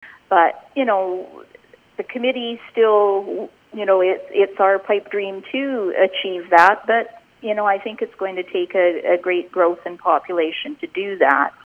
It’s still not the 24/7 emergency coverage Kaslo once had but Mayor Suzan Hewat says it’s positive news.